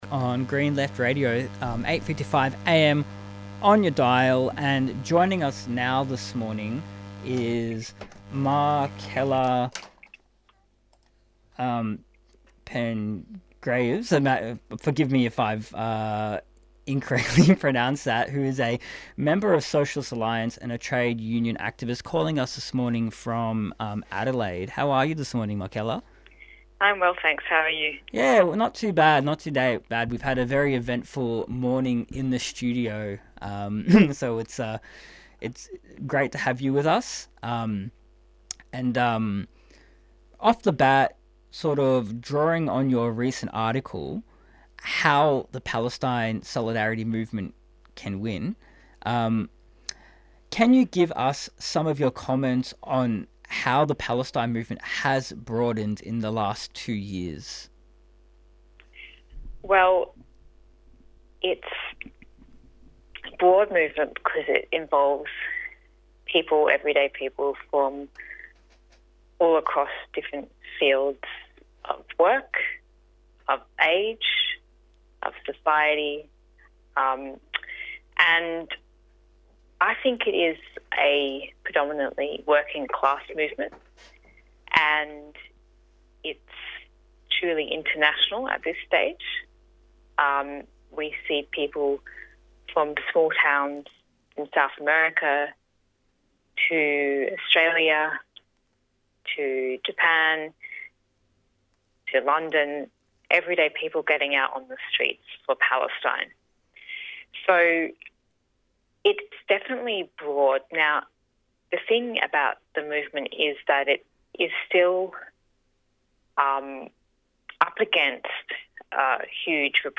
Newsreports Headline news discussion from the presenters responding to the the government's proposed sweeping antisemitism plan which is a conscious attempt to attack the Palestine solidarity movement and democratic rights. Interviews and Discussion